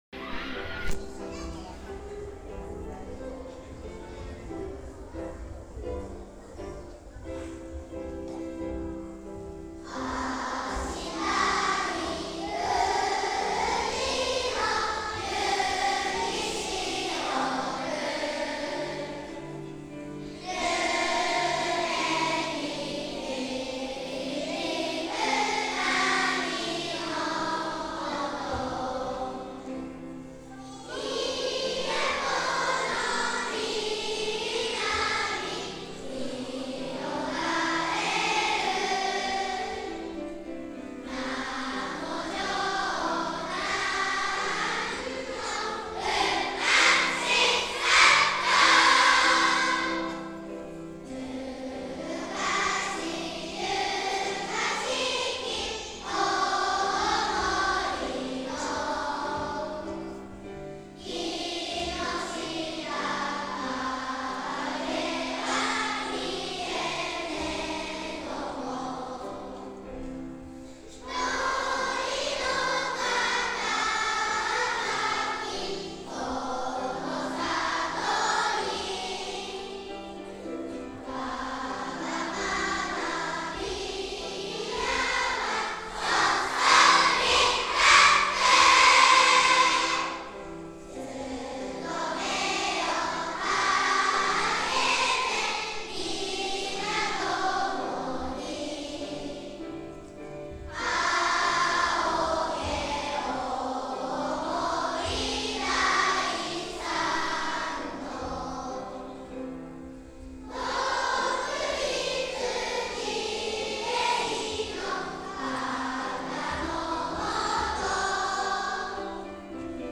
校章・校歌
校歌音源（スピードゆっくりめ）（音楽ファイル(MP3)：2,625KB）